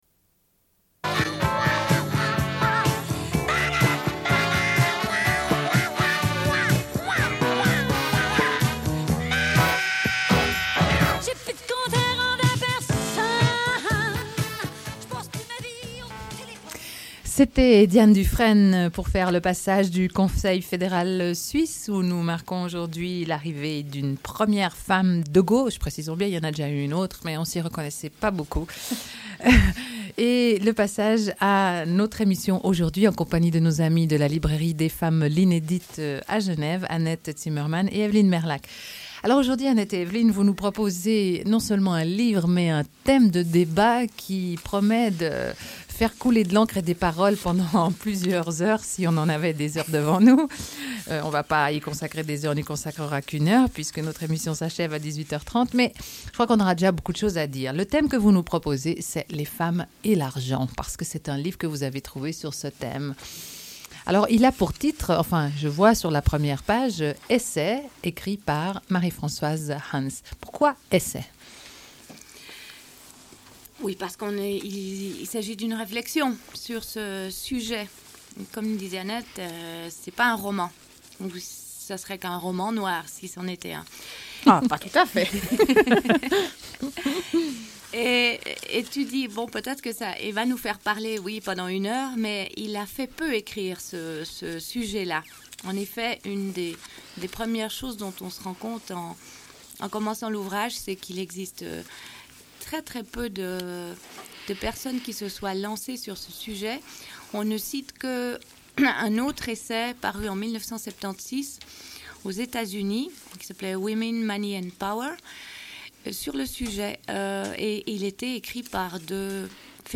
Une cassette audio, face B29:06